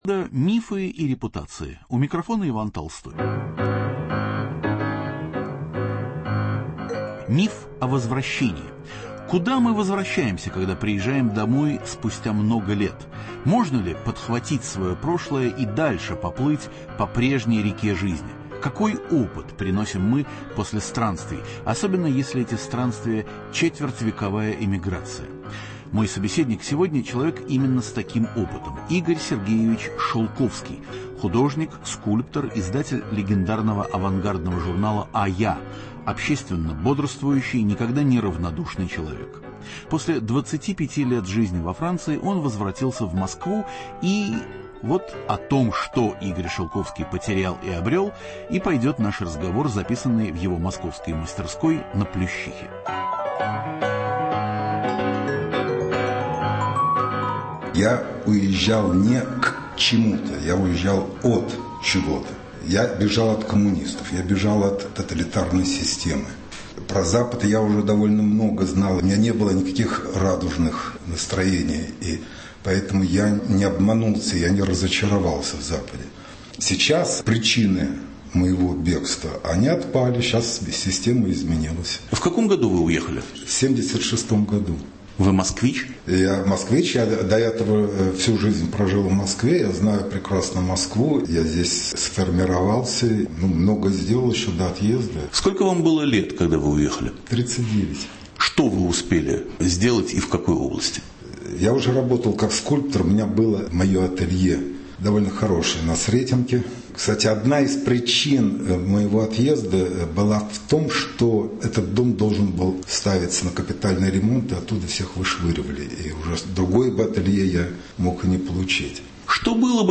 Миф о возвращении. Интервью